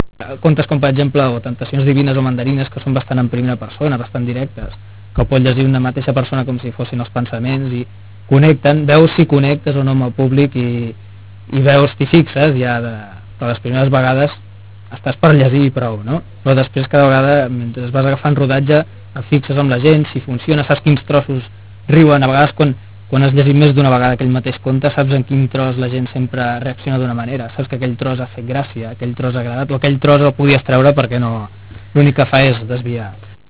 EntrevistaMostra Sonora - 33 seg.